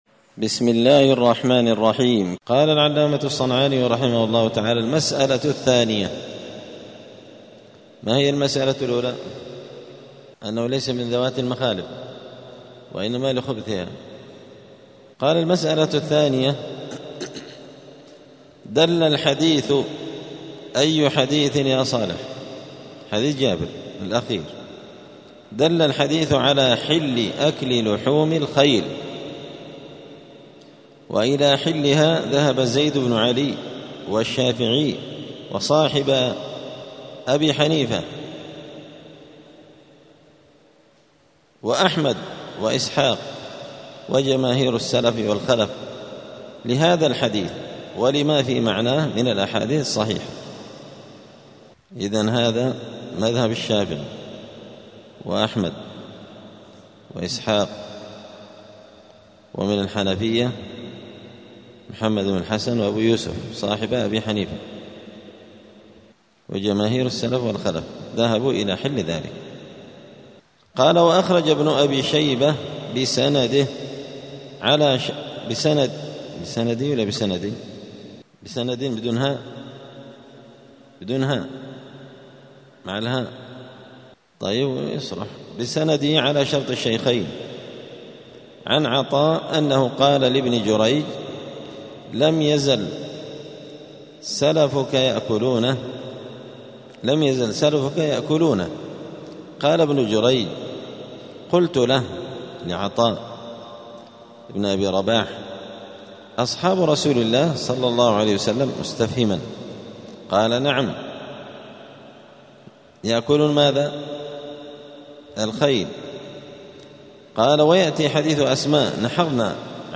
*الدرس الثالث (3) {حكم أكل لحم الخيل}*